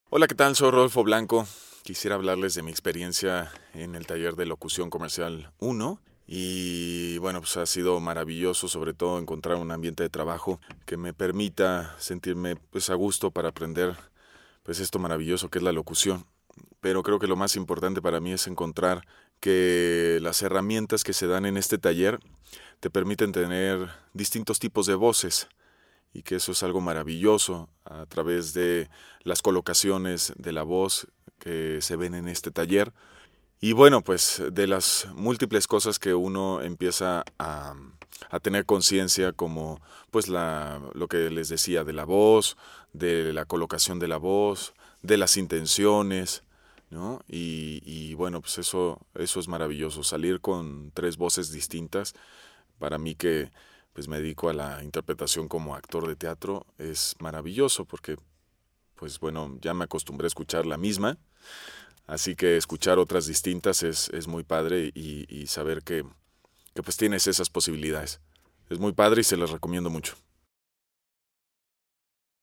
Locución Comercial